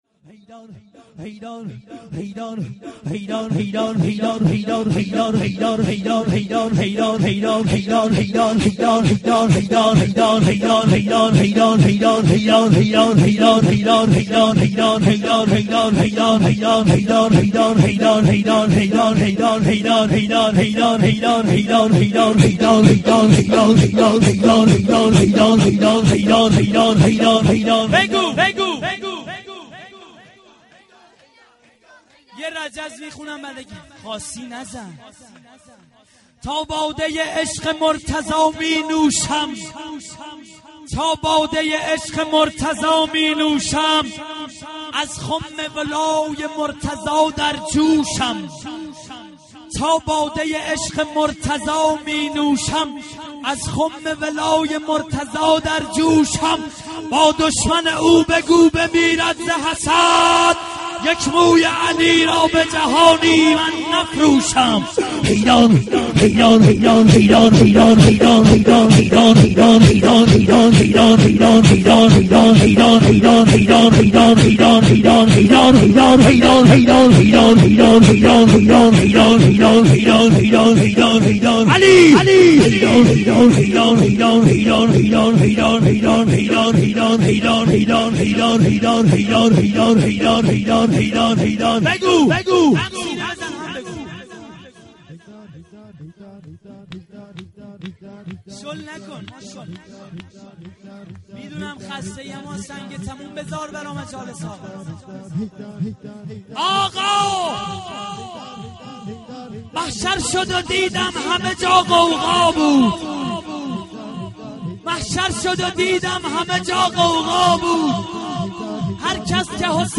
با بهشت کاری ندارم ای خدا.../شور